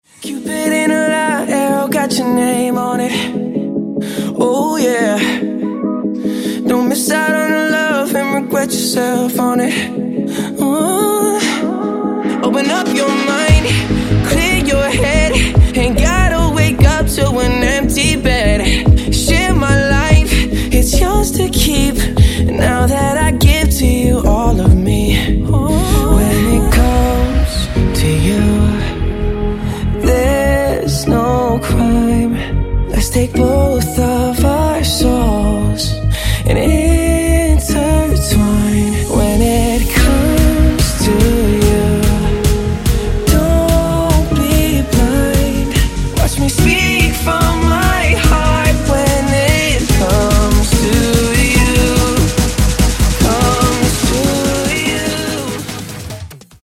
• Качество: 128, Stereo
поп
мужской вокал
dance
Melodic
romantic
vocal